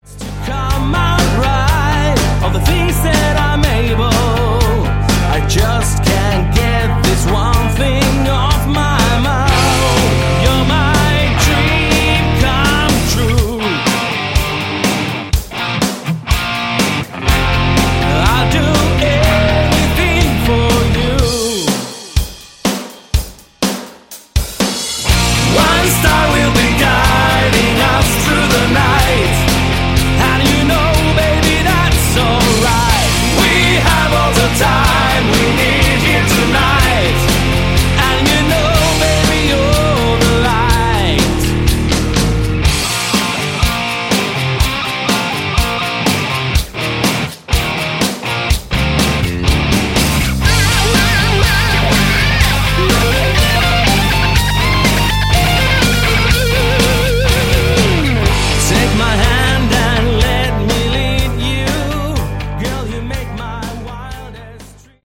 Category: Hard Rock
lead vocals
bass
guitar, vocals
drums